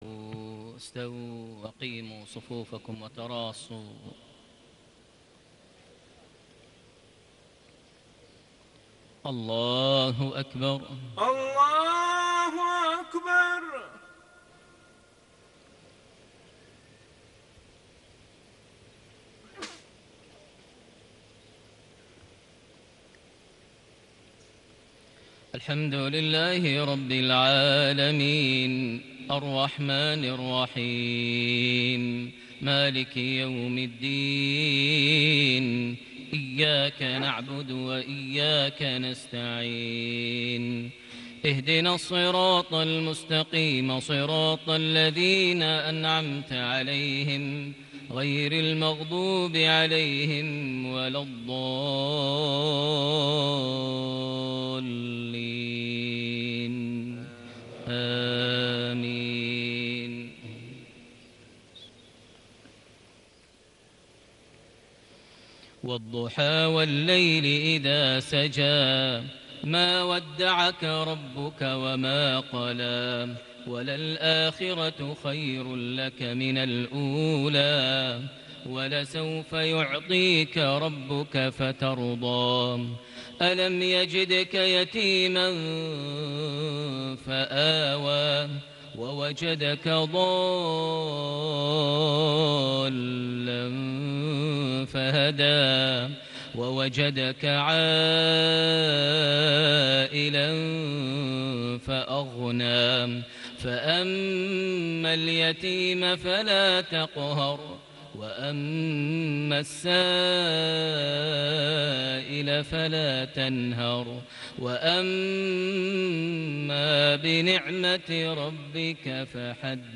صلاة المغرب ١٨ شعبان ١٤٣٨هـ سورتي الضحى / الشرح > 1438 هـ > الفروض - تلاوات ماهر المعيقلي